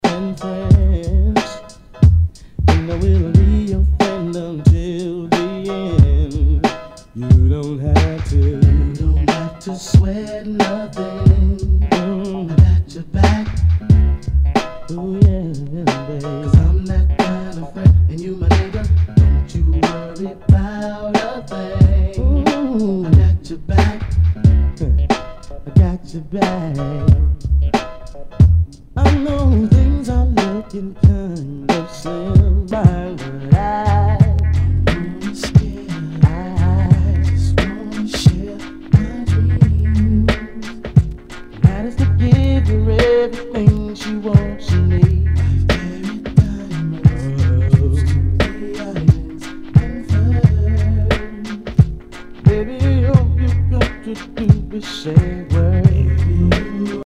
HIPHOP/R&B
ナイス！ネオ ソウル / R&B！！